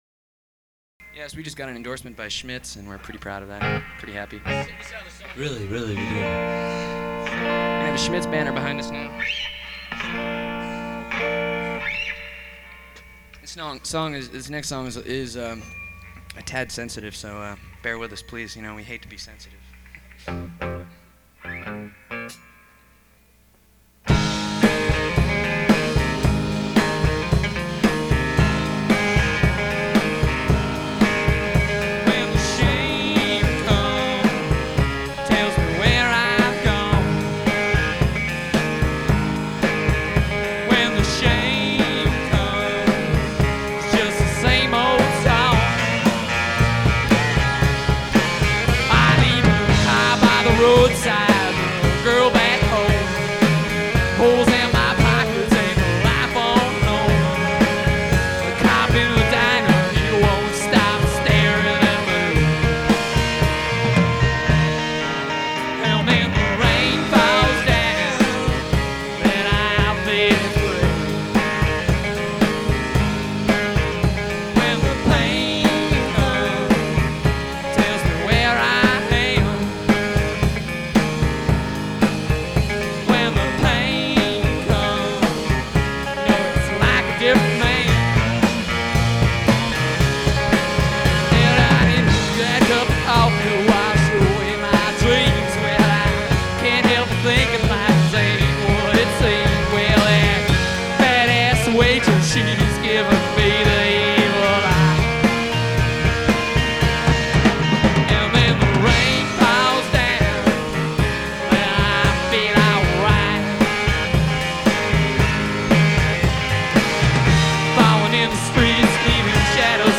Tag: Alt-Country